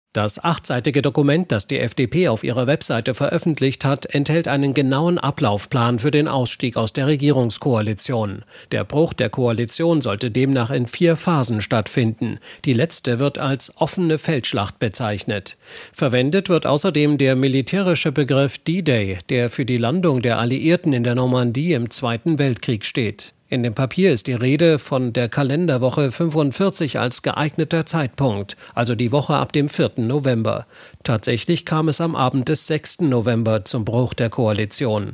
Nachrichten Nach Veröffentlichung von FDP-Papier: SPD fordert Entschuldigung